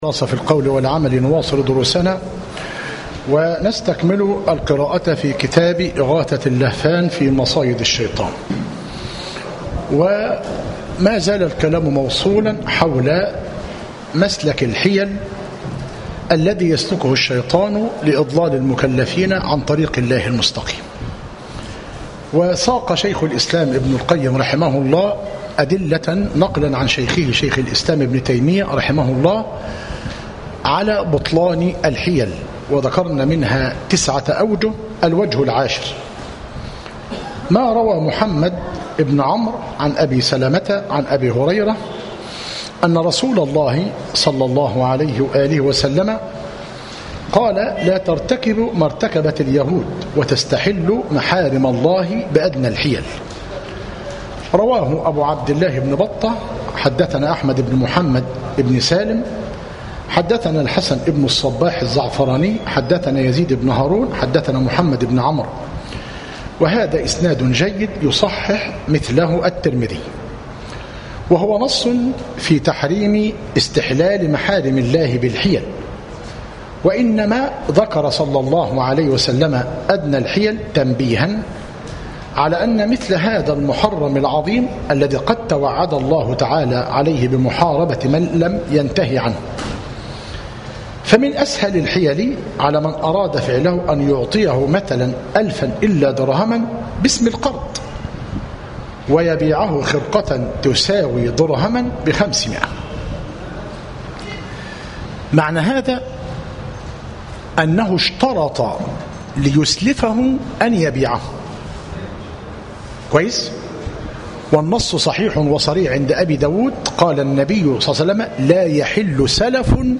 إغاثة اللهفان - مسجد عباد الرحمن - المنايل - كفر حمزة - قليوبية - المحاضرة الستون - بتاريخ 17- شوال- 1436هـ الموافق 2- أغسطس- 2015 م